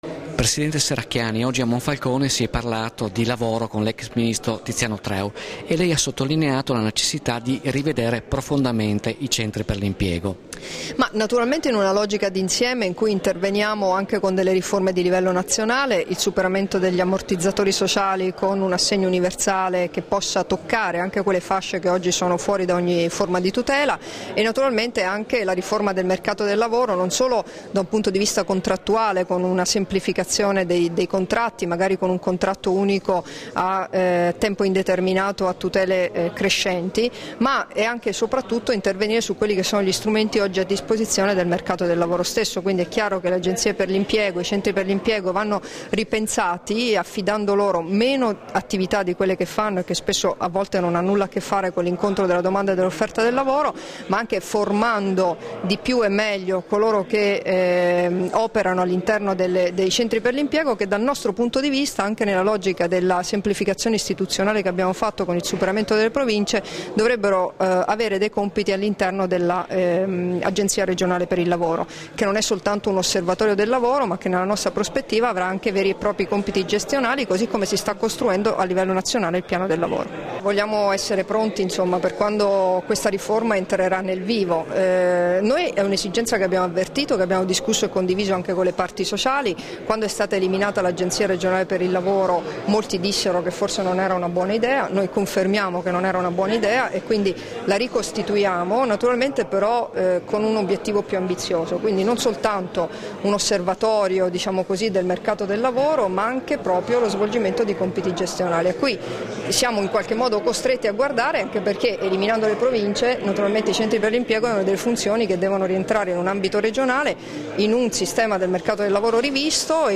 Dichiarazioni di Debora Serracchiani (Formato MP3) sulla revisone dei Centri per l'Impiego, rilasciate a margine della presentazione del volume curato da Tiziano Treu ''Welfare aziendale. Migliorare la produttività e il benessere dei dipendenti'', all'Europalace Hotel a Monfalcone il 3 marzo 2014 [1997KB]